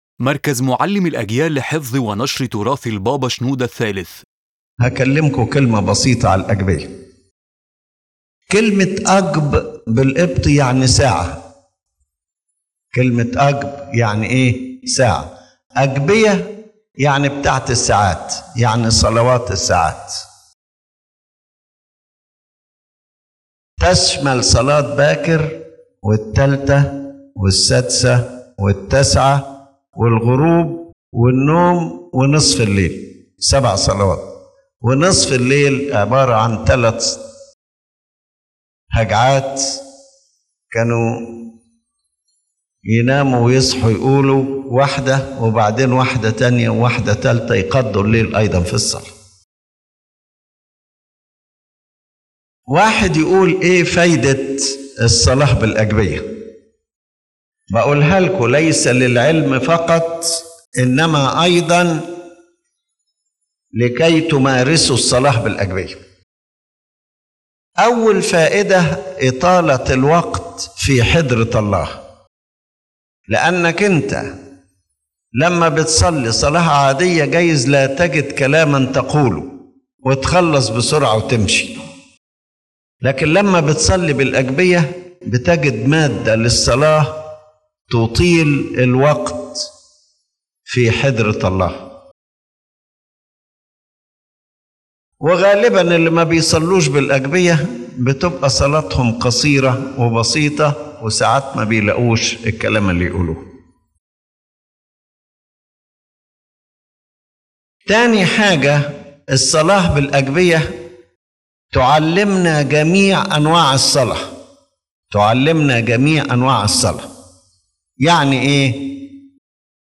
His Holiness Pope Shenouda III speaks about the Agpeya as a complete spiritual school, not merely a book of prayers, explaining its doctrinal and spiritual value in the life of the believer.